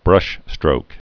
(brŭshstrōk)